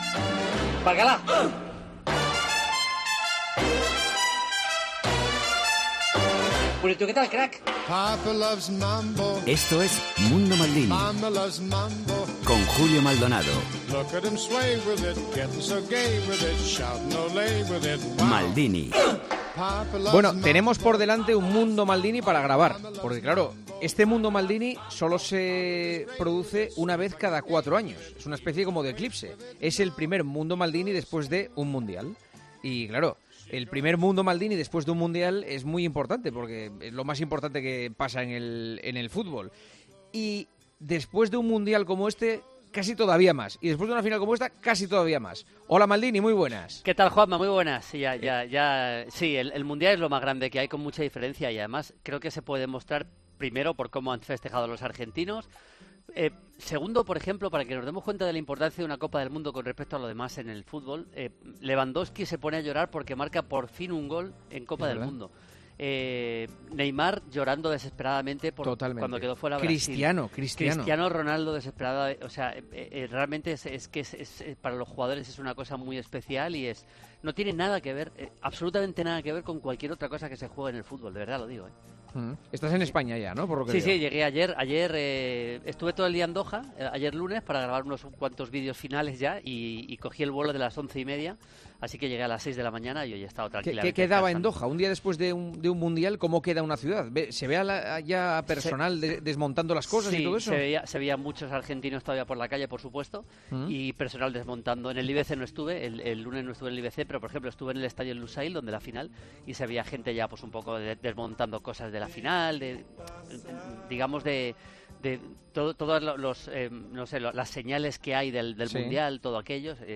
AUDIO: Julio Maldonado 'Maldini' analiza el Mundial de Qatar y responde a las preguntas de los oyentes.